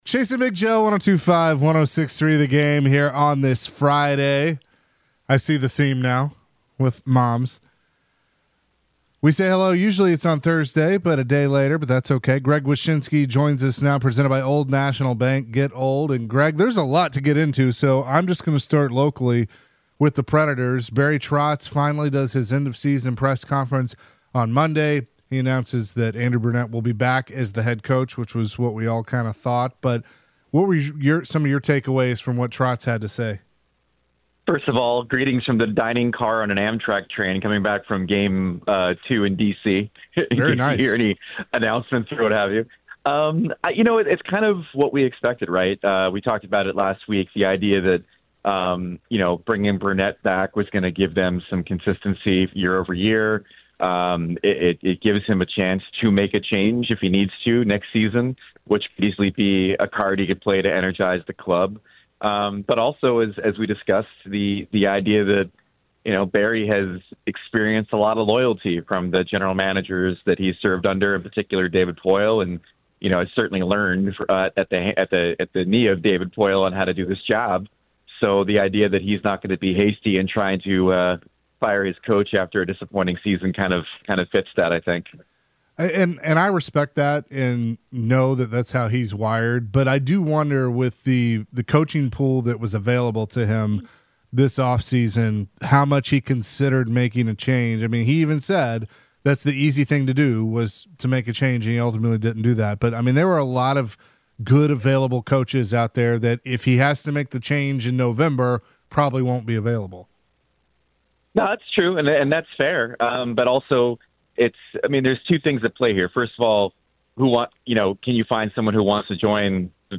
ESPN NHL Analyst Greg Wyshynski joined the show to talk all things hockey and what the future holds for Andrew Brunette and the Nashville Predators. Plus, Greg shared his thoughts on the NHL Playoffs.